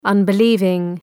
Προφορά
{,ʌnbı’lıvıŋ}